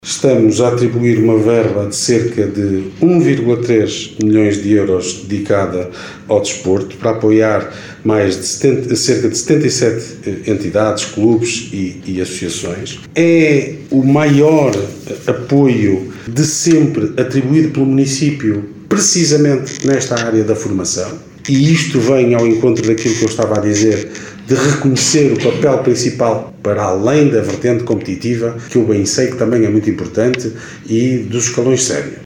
O anúncio foi feito pelo autarca vimaranense, Ricardo Araújo, no âmbito do Fórum do desporto, que decorreu este sábado no Multiusos de Guimarães.